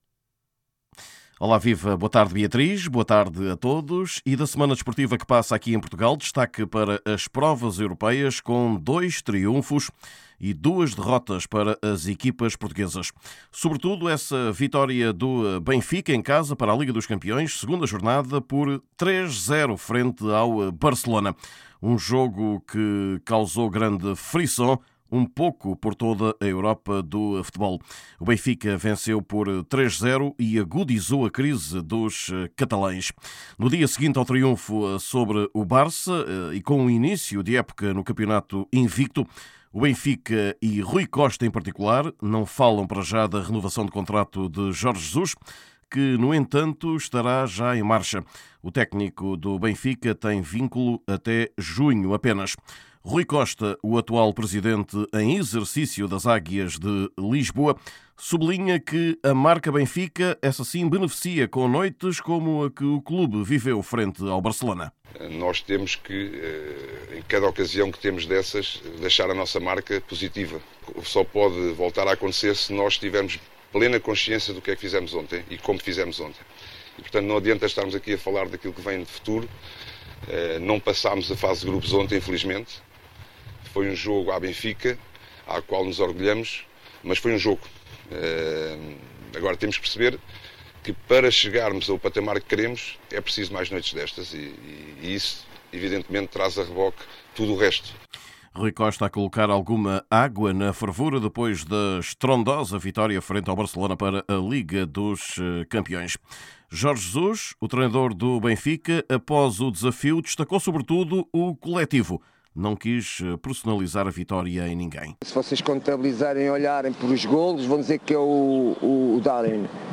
Escutaremos o seleccionador luso, Fernando Santos.